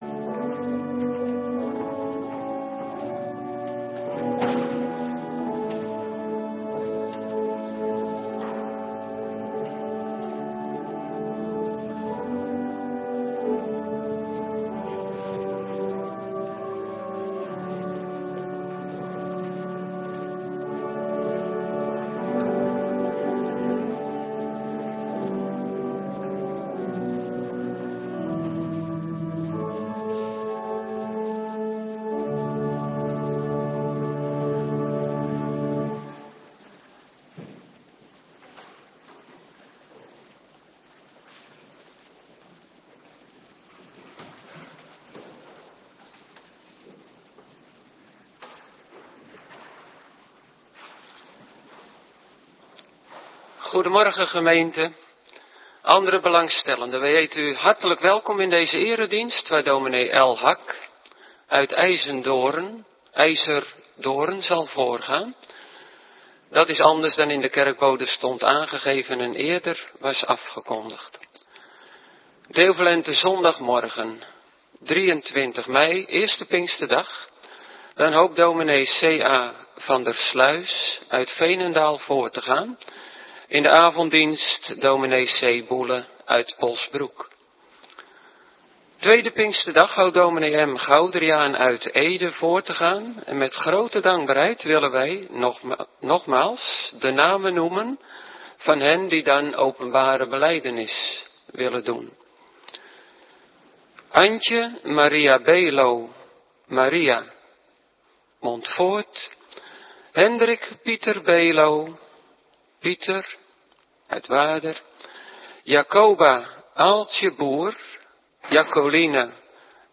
Morgendienst - Cluster 1
Locatie: Hervormde Gemeente Waarder